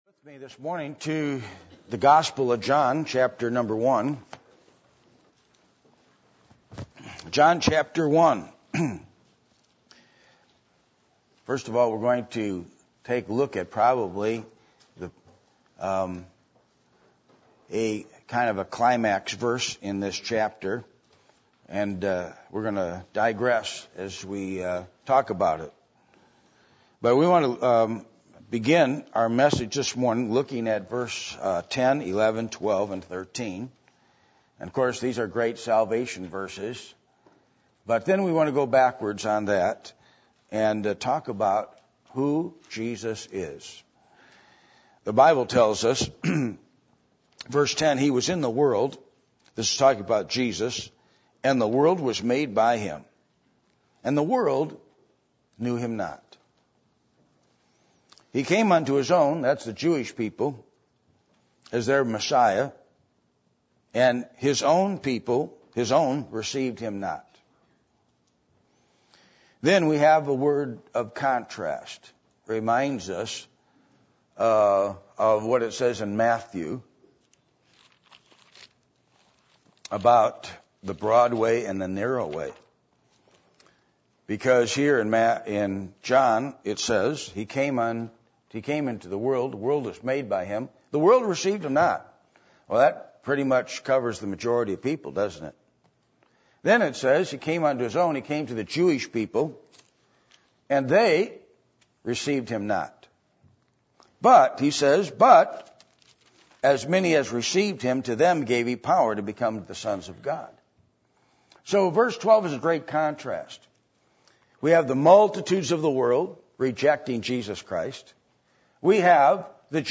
Passage: John 1:10-13, Matthew 7:13-14 Service Type: Sunday Morning %todo_render% « A Study Of Deuteronomy 6